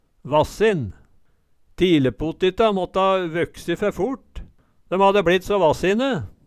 vassin - Numedalsmål (en-US)